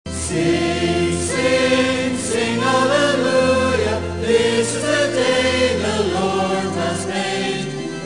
Donaghmore Choir: